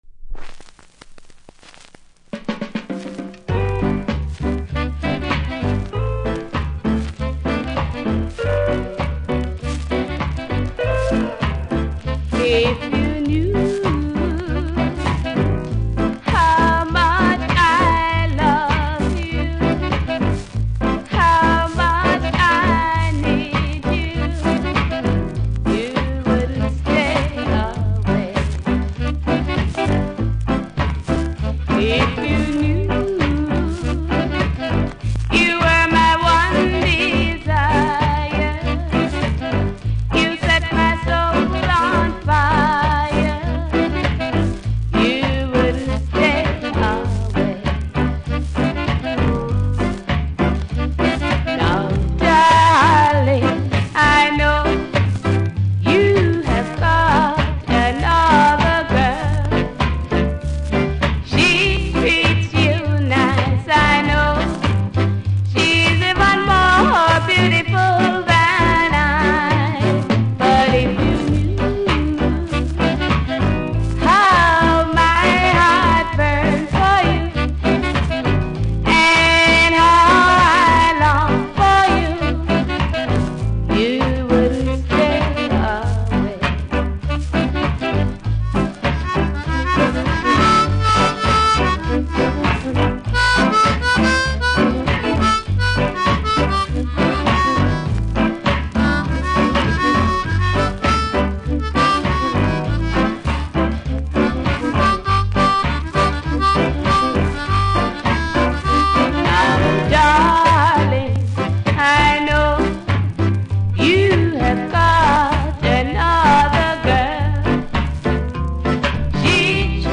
ジャマイカ盤なので多少のプレスノイズありますので試聴で確認下さい。